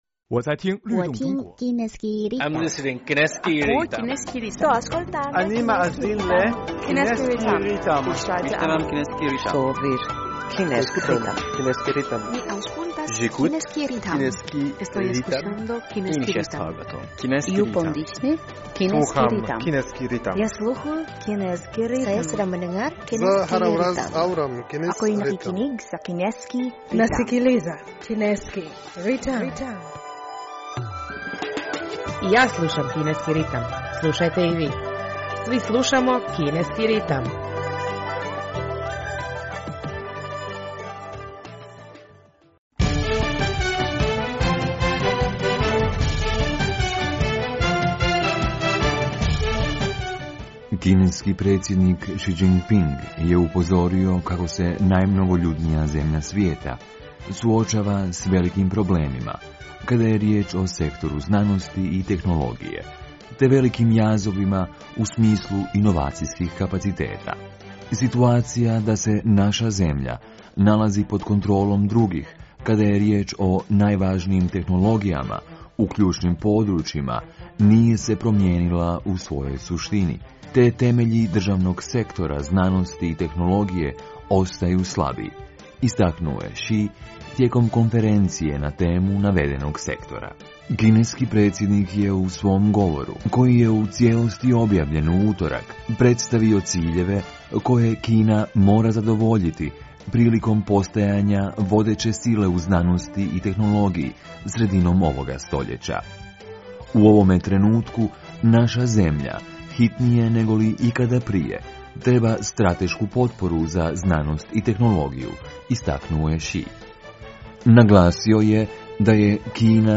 U današnjoj emisiji prije svega poslušajte novosti iz Kine i svijeta, a zatim našu rubriku "U fokusu Kine".